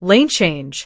audio_lanechange.wav